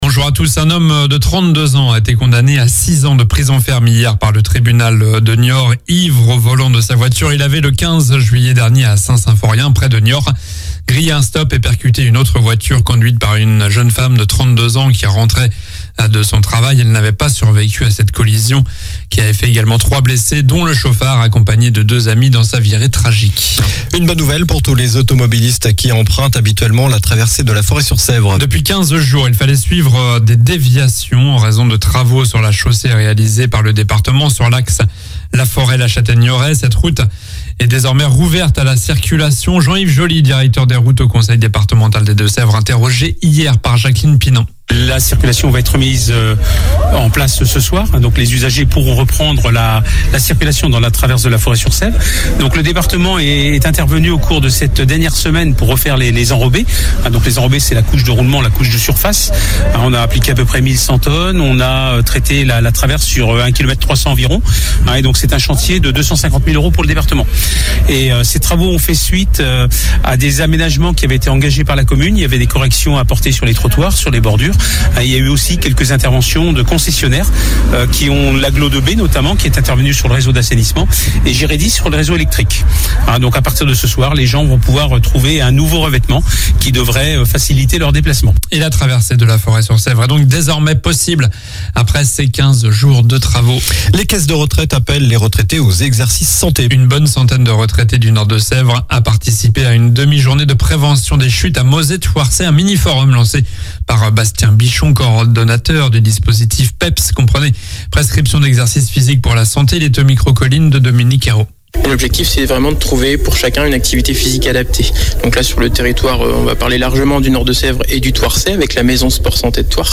Journal du samedi 30 septembre (matin)